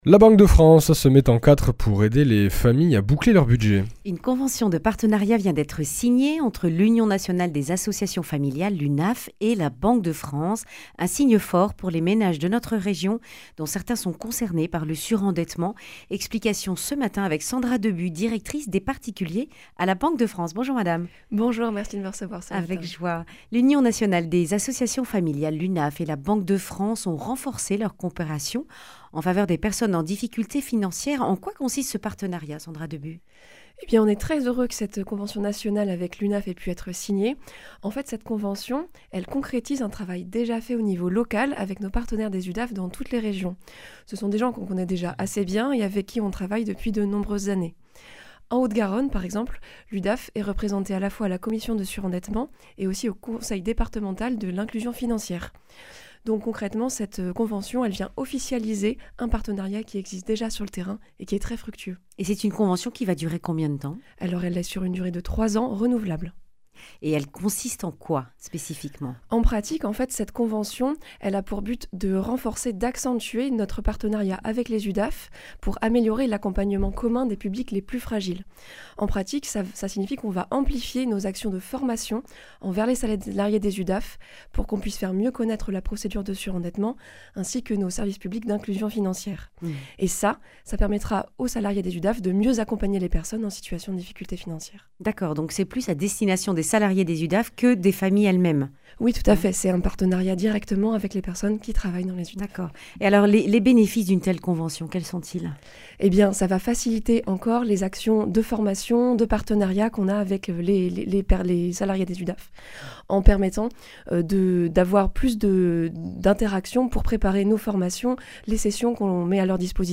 Accueil \ Emissions \ Information \ Régionale \ Le grand entretien \ Surendettement des ménages : la Banque de France au plus proche des (...)